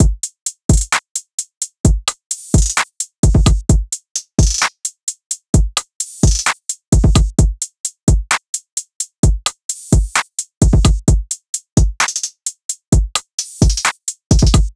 SOUTHSIDE_beat_loop_trill_full_01_130.wav